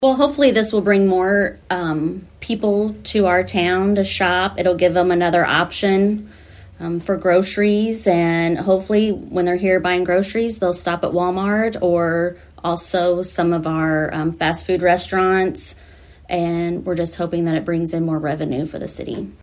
Desloge City Administrator Stephanie Daffron explains what the new business hopefully means for the area.